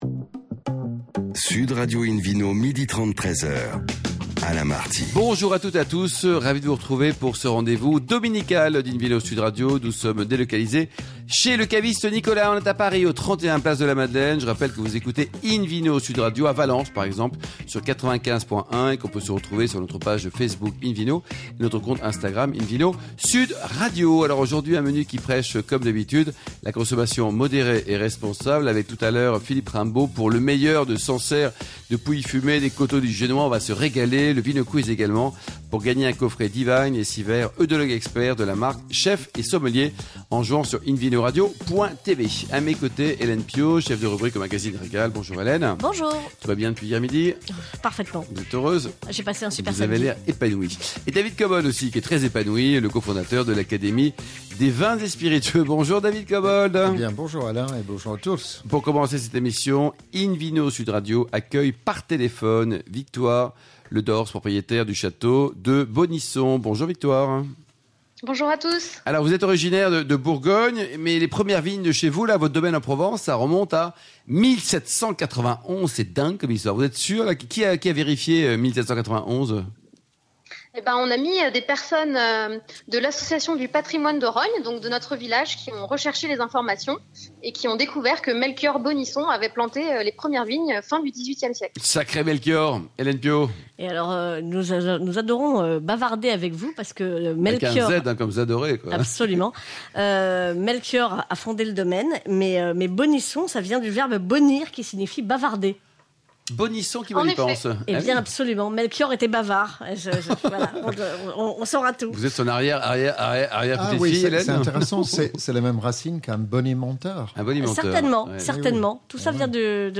Ce dimanche 17 octobre 2021 nous étions de passage sur Sud Radio dans l’émission « In Vino ».